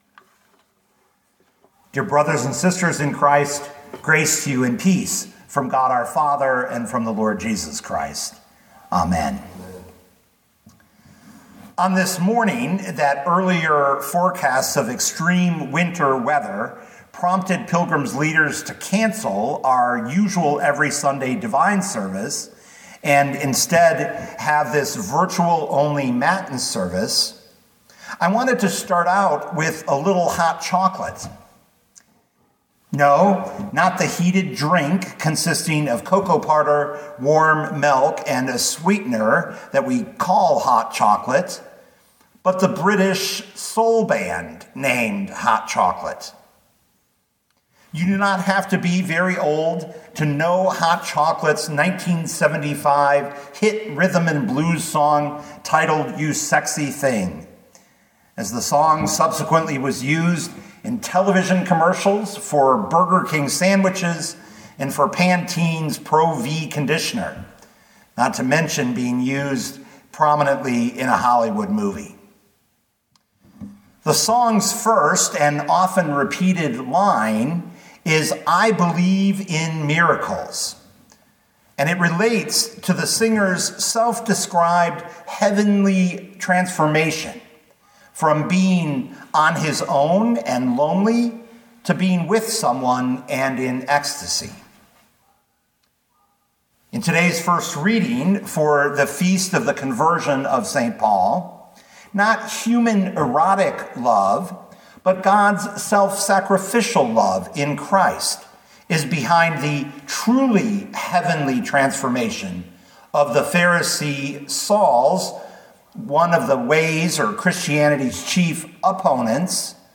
2026 Acts 9:1-22 Listen to the sermon with the player below, or, download the audio.